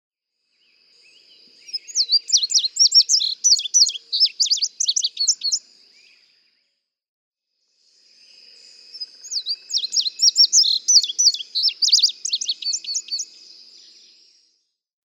Example 3. Indigo bunting: One song from each of two neighboring indigo buntings with similar songs (♫202).
Francis Marion National Forest, South Carolina.
♫202—one song from each of two neighboring males
202_Indigo_Bunting.mp3